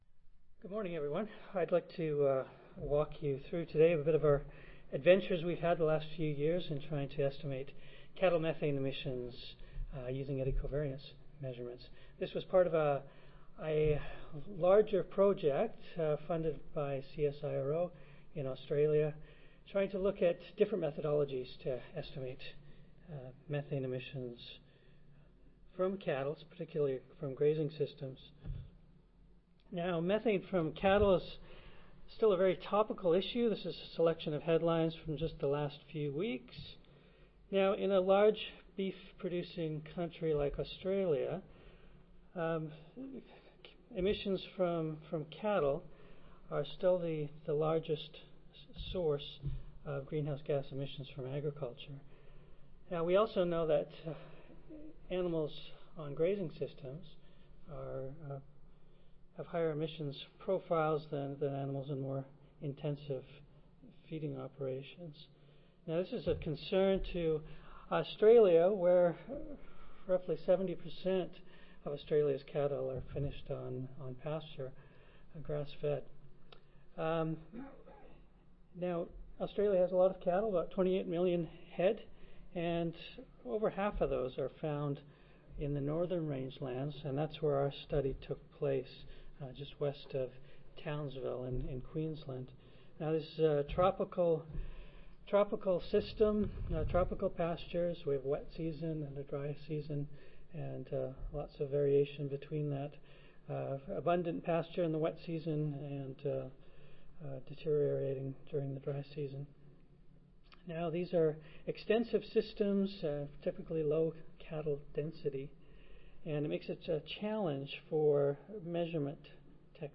See more from this Division: ASA Section: Environmental Quality See more from this Session: Greenhouse Gas Emissions from Integrated-Crop Livestock System Oral